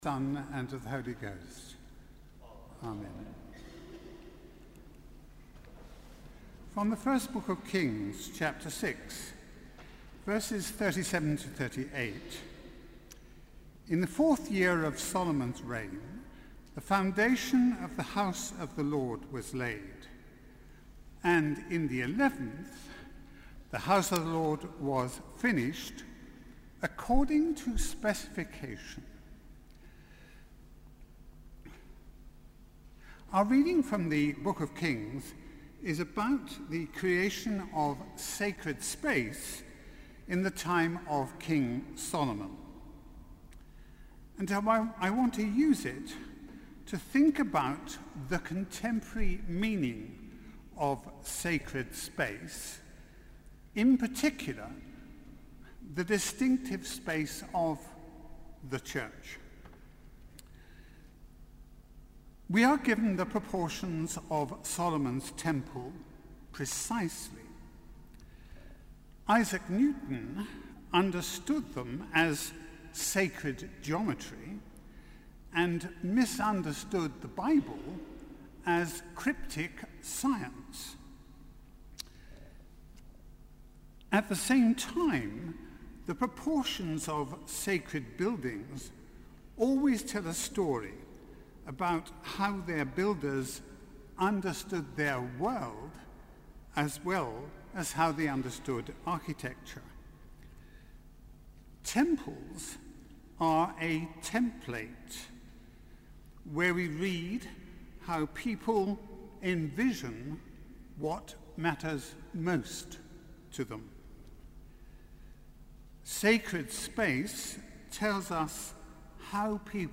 Sermon: Evensong - 27 July 2014